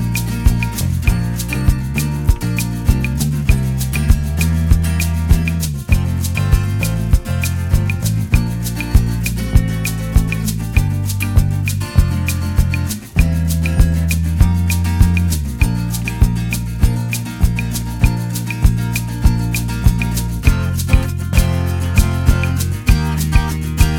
No Piano At All Pop (1980s) 2:57 Buy £1.50